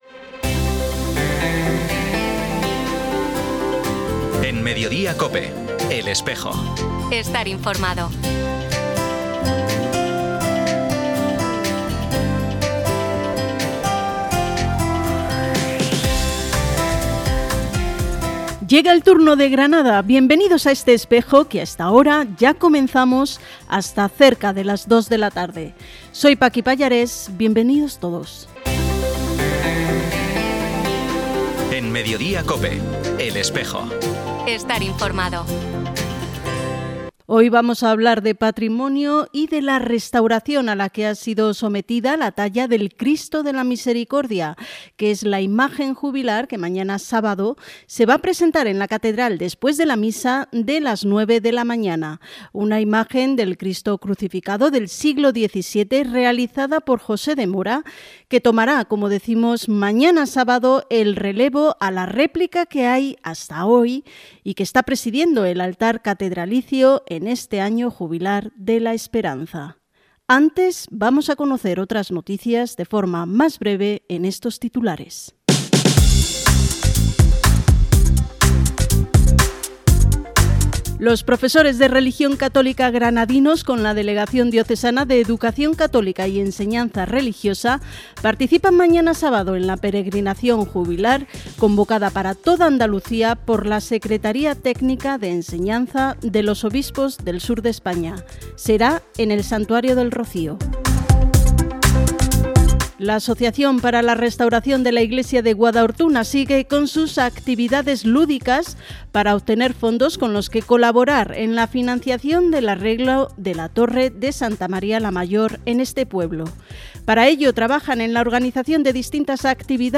Entrevistas sobre ambos temas en el programa emitido hoy 14 de febrero en COPE Granada y COPE Motril.